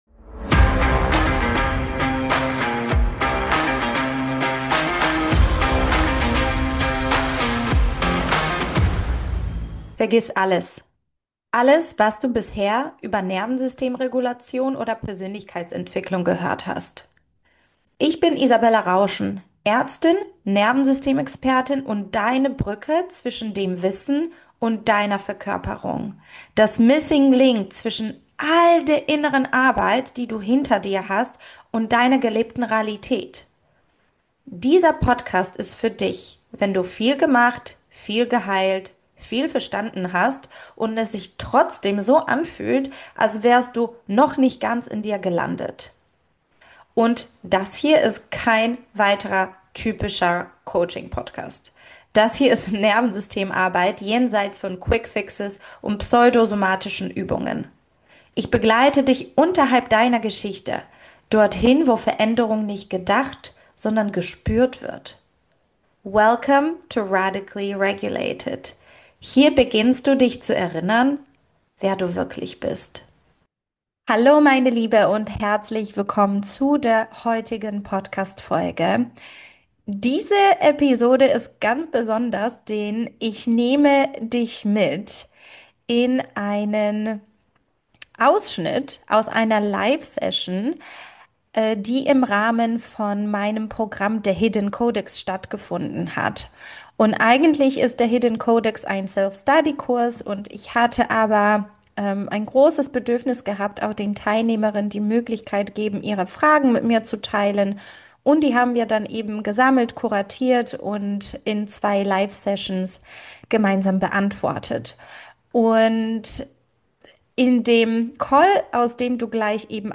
In dieser Folge hörst du einen Ausschnitt aus einer Live Q&A Session in meinem Programm The Hidden Codex. Wir sprechen über das Gefühl, nie wirklich angekommen zu sein, und was es wirklich bedeutet, wenn das Nervensystem ständig sucht: nach „dem richtigen Ort“, „dem richtigen Job“, „dem richtigen Leben“.